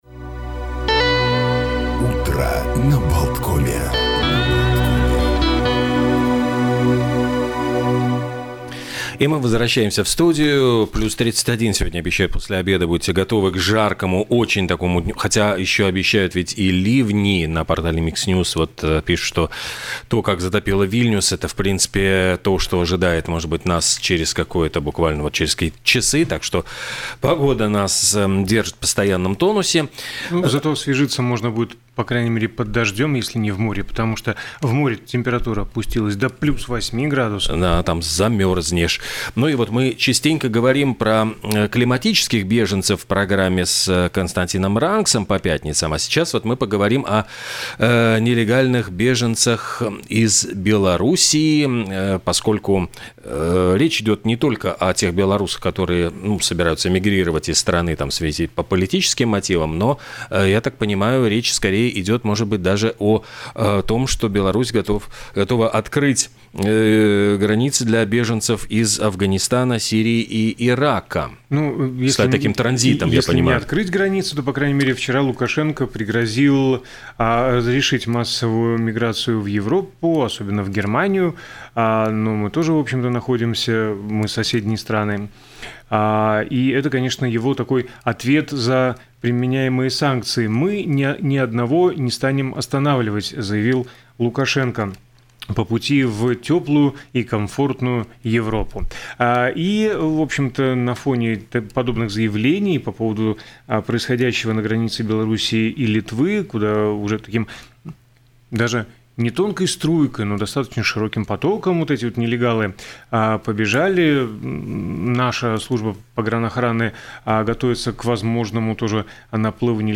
В некоторых пунктах пересечения границы введут тестирование на месте. Об это в эфире радио Baltkom заявил заместитель начальника Государственной пограничной охраны (ГПО) Иварс Рускулис.